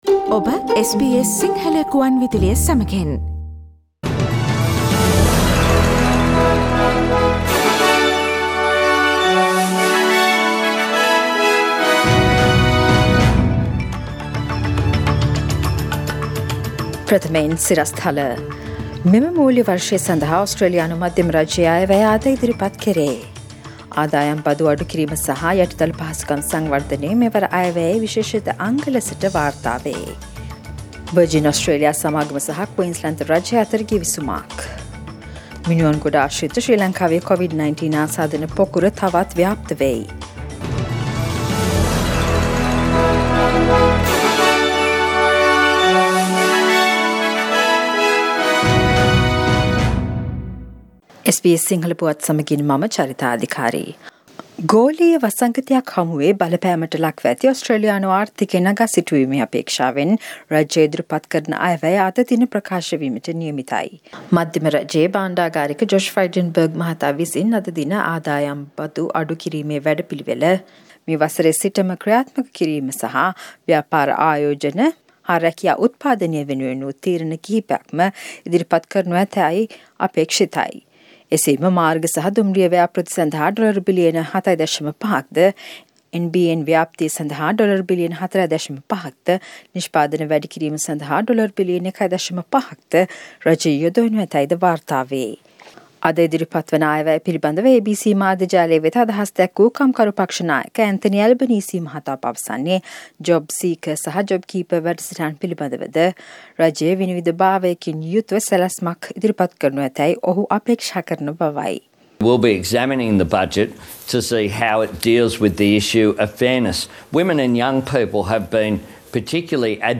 Daily News bulletin of SBS Sinhala Service: Tuesday 06th of October 2020
Today’s news bulletin of SBS Sinhala Radio – Tuesday 06th of October 2020 Listen to SBS Sinhala Radio on Monday, Tuesday, Thursday and Friday between 11 am to 12 noon